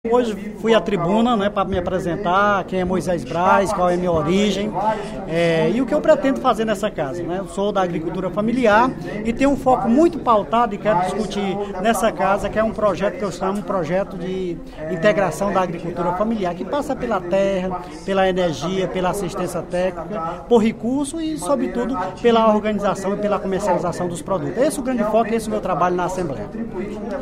O deputado Moisés Braz (PT) apresentou, durante o primeiro expediente da sessão plenária desta quarta-feira (11/02), as bandeiras que pretende defender durante o seu mandato parlamentar. Entre os temas, estão a defesa dos governos de Camilo Santana e da presidente Dilma Rousseff, a valorização dos direitos dos trabalhadores, e o debate sobre o enfrentamento entre a agricultura familiar e o agronegócio.